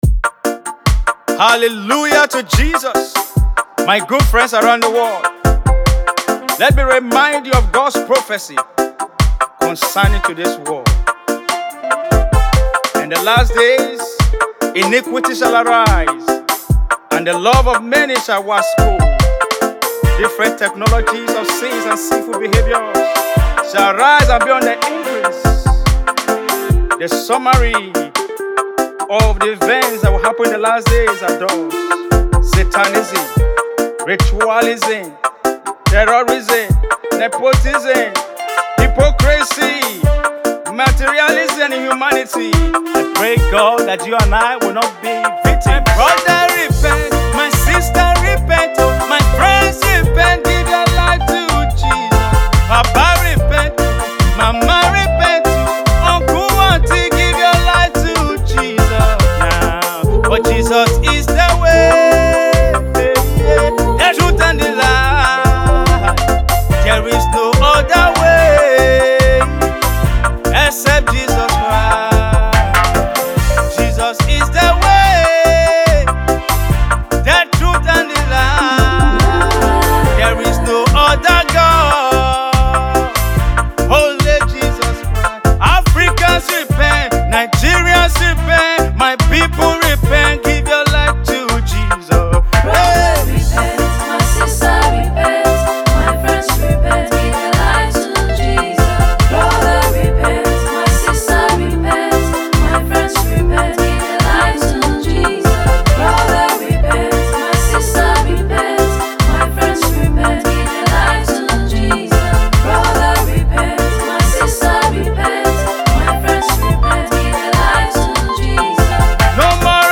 Gospel
Gospel release